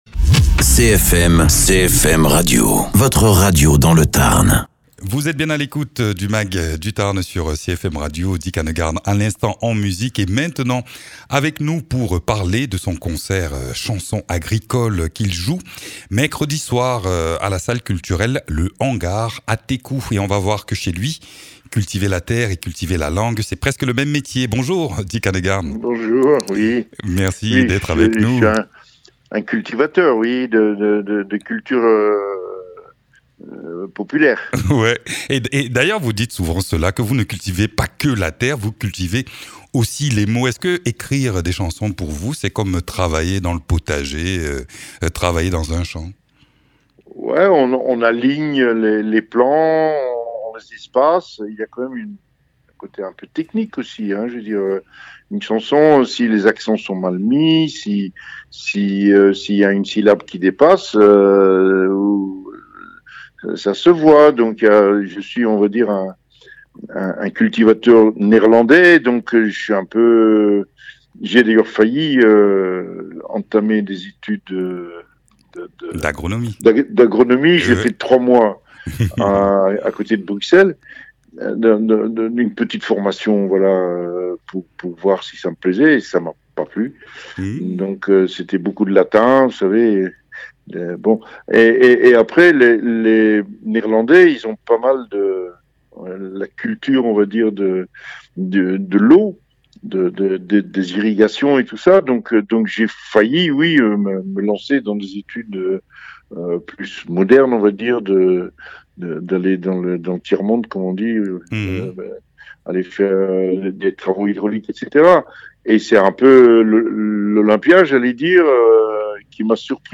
Interviews
Invité(s) : Dick Annegarn, musicien-chanteur-poète.